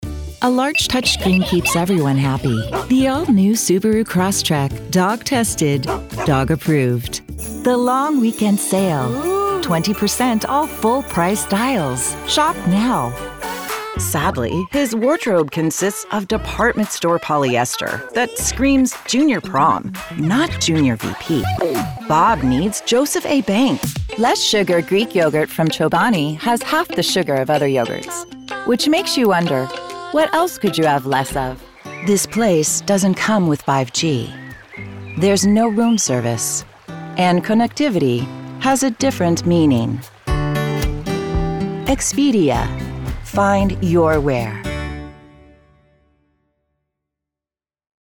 Commercial
California/Valley Girl
Middle Aged
My voice is best for female adult/middle aged/senior. (Age 28+)